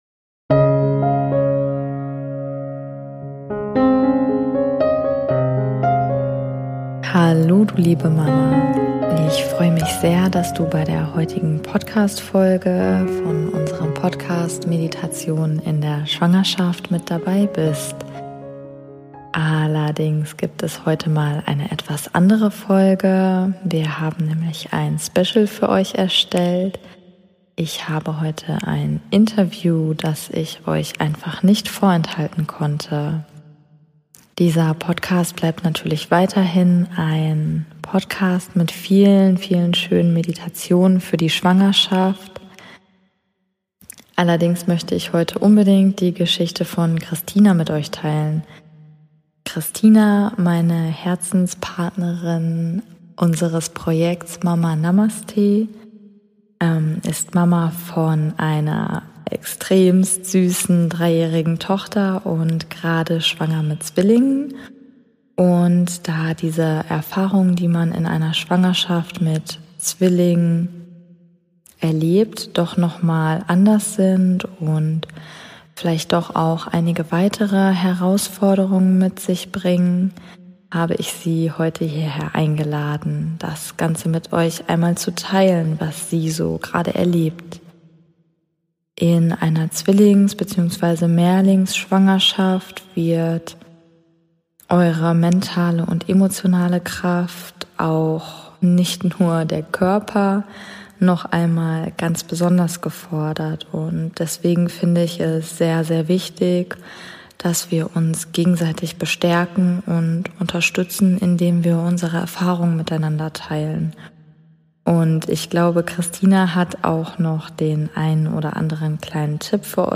#005 - Interview Special - Gebärmutterhalsverkürzung ~ Meditationen für die Schwangerschaft und Geburt - mama.namaste Podcast